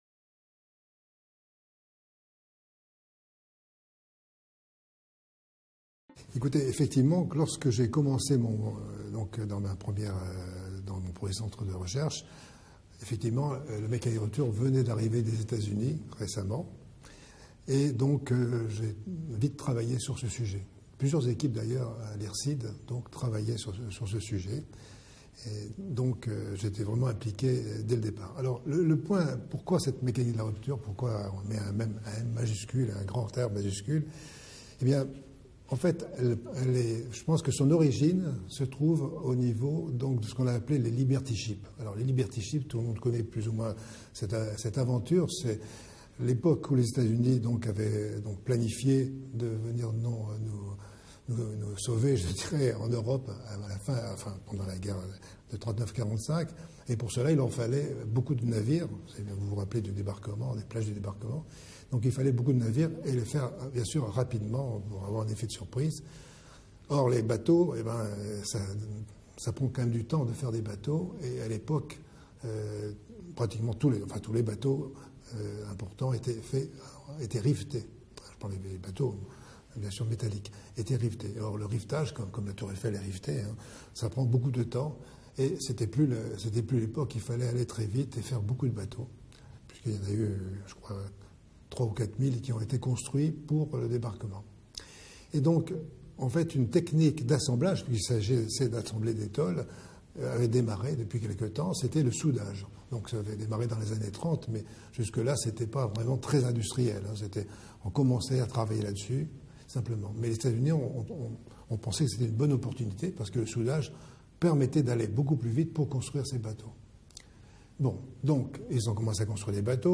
Les 11 vidéos que nous vous proposons dans ce grain peuvent être classées en deux parties : les 8 premières sont basées sur l’interview